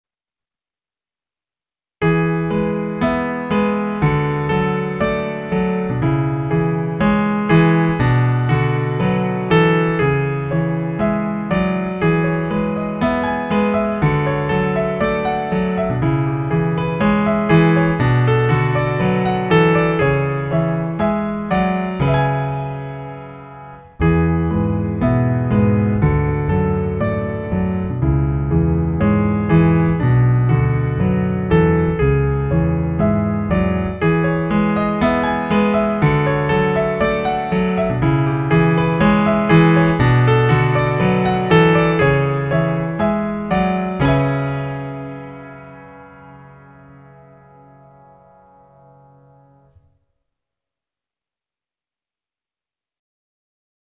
solo piano, repos, douceur, pubs